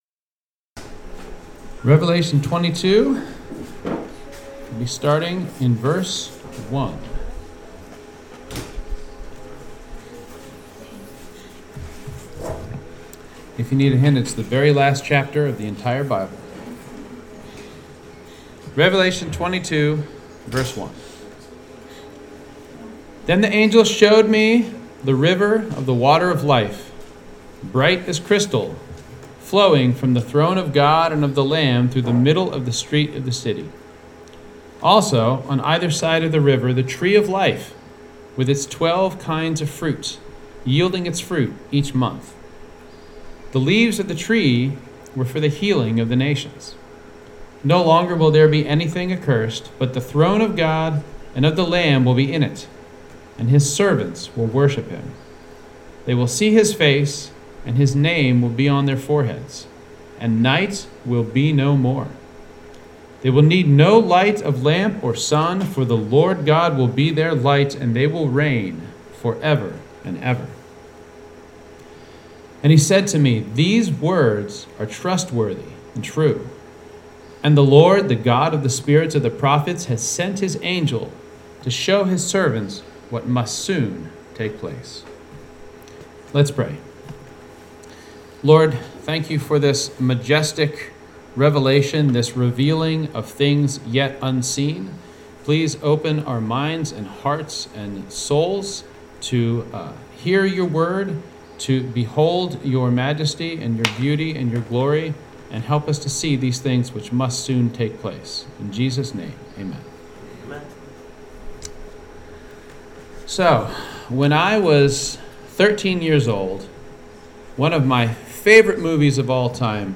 2025 Stronghold Sermon Series #6: The Future